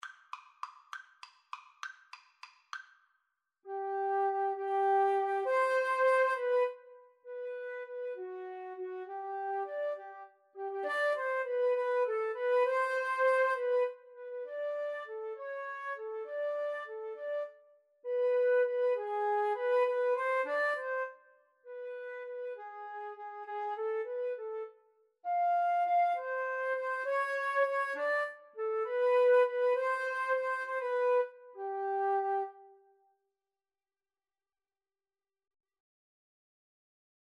3/8 (View more 3/8 Music)
Classical (View more Classical Flute Duet Music)